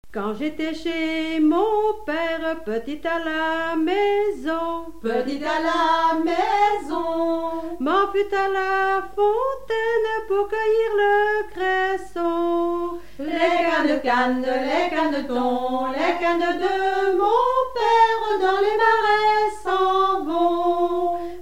Saint-Gervais
Genre laisse
Pièce musicale inédite